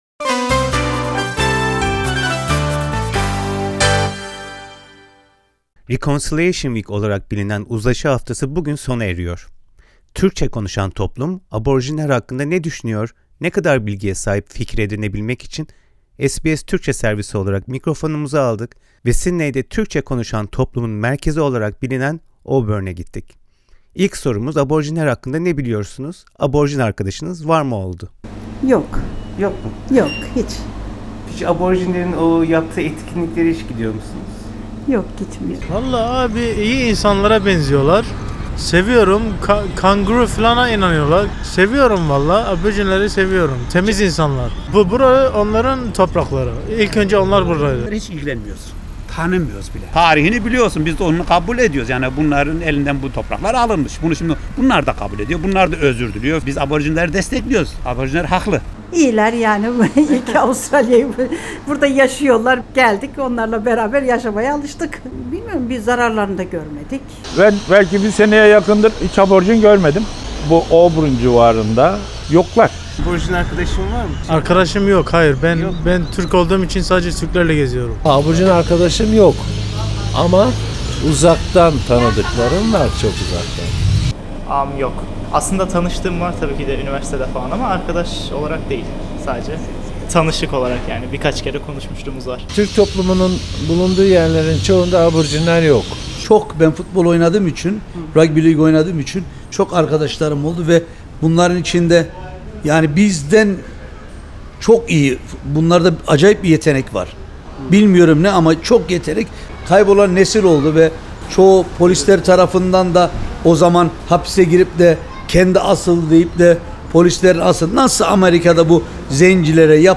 Reconciliation Week olarak bilinen Uzlaşı Haftası bugün sona eriyor. Türkçe konuşan toplum Aborjinler hakkında ne düşünüyor, ne kadar bilgiye sahip fikir edinebilmek için SBS Türkçe servisi olarak mikrofonumuzu aldık ve Sydney’de Türkçe konuşan toplumun merkezi olarak bilinen Auburn’e gittik.